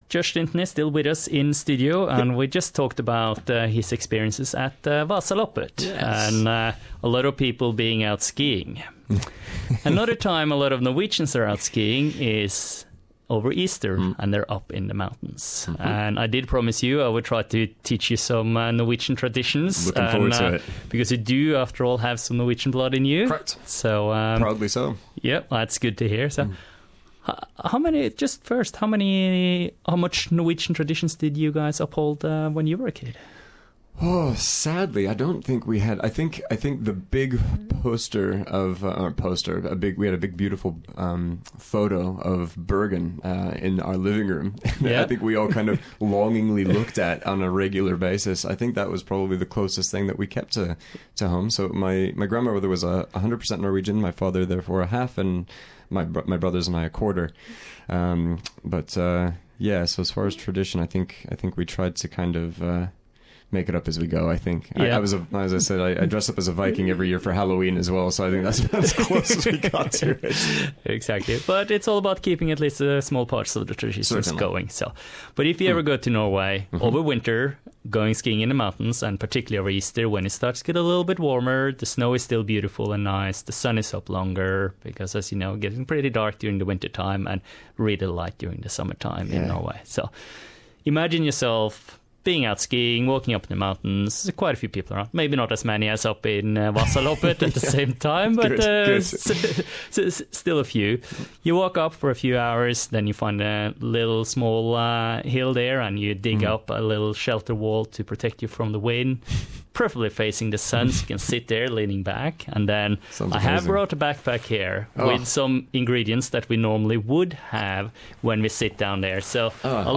A taste of Norwegian easter tradtions in SBS Norwegian's studio